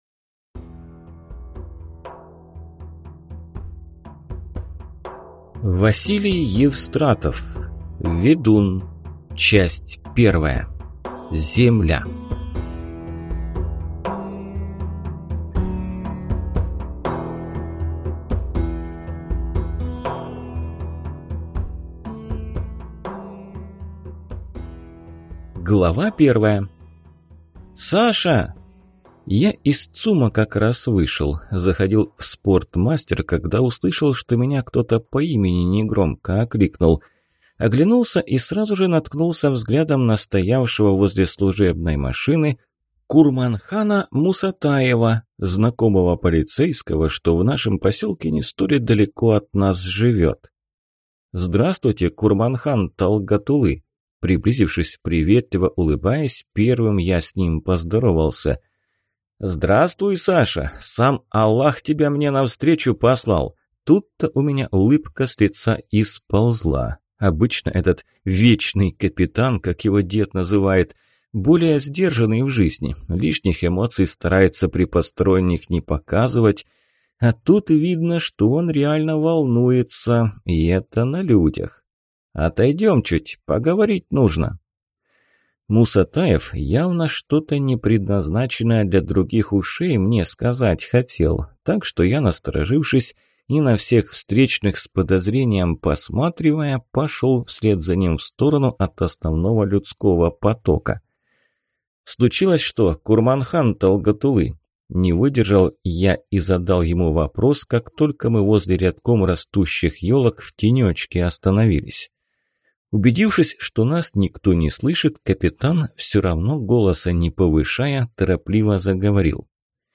Aудиокнига Ведун